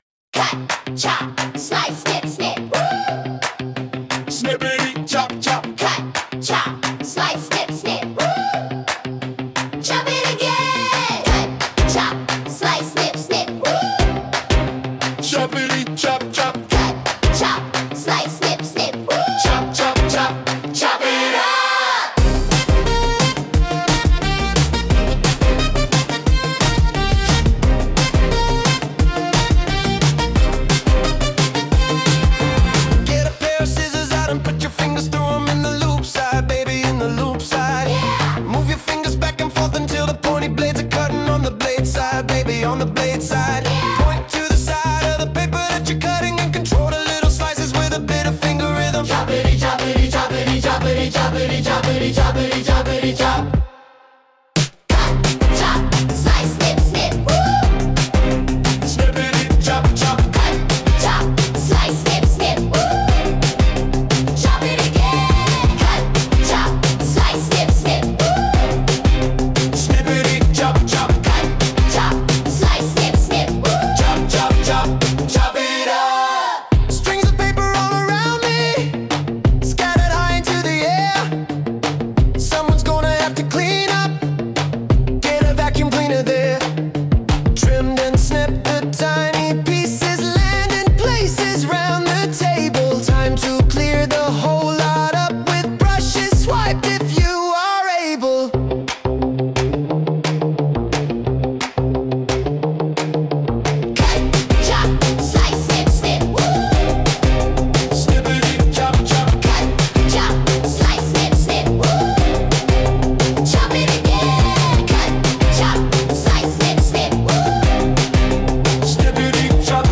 Lyrics : By me
Sung by Suno